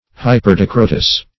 Hyperdicrotous \Hy`per*di"cro*tous\